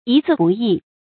一字不易 注音： ㄧ ㄗㄧˋ ㄅㄨˋ ㄧˋ 讀音讀法： 意思解釋： 文字精醇，一個字也不能更改。